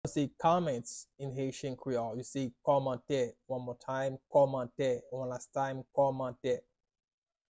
“Comments” in Haitian Creole – “Kòmantè” pronunciation by a native Haitian tutor
“Kòmantè” Pronunciation in Haitian Creole by a native Haitian can be heard in the audio here or in the video below:
How-to-say-Comments-in-Haitian-Creole-–-Komante-pronunciation-by-a-native-Haitian-tutor.mp3